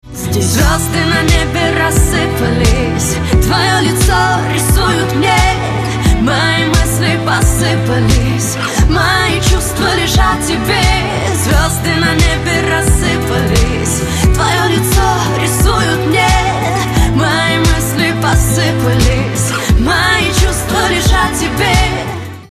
• Качество: 128, Stereo
поп
Новинка от молодых певиц